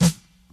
• Boom Acoustic Snare E Key 113.wav
Royality free snare drum sample tuned to the E note. Loudest frequency: 1696Hz
boom-acoustic-snare-e-key-113-g3y.wav